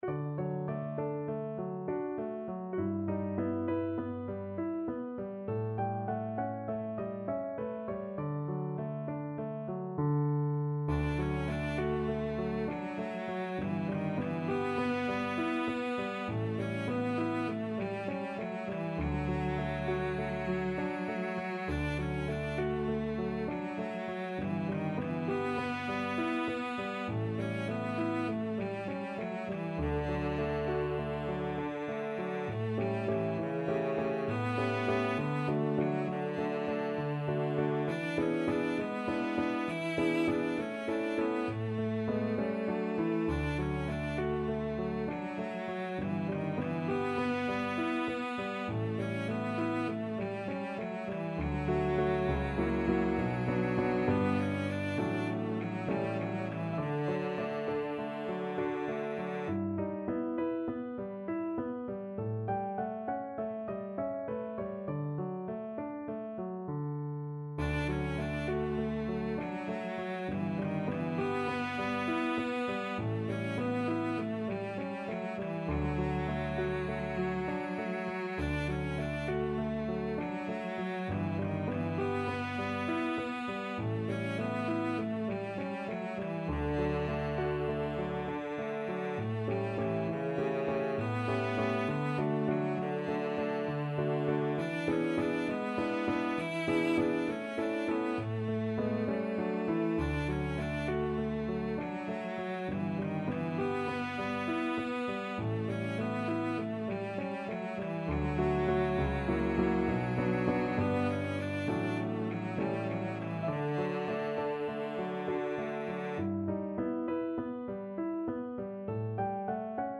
Pop Stephen Foster Beautiful Dreamer Cello version
Cello
Beautiful Dreamer is a parlor song by Stephen Foster.
D major (Sounding Pitch) (View more D major Music for Cello )
Moderato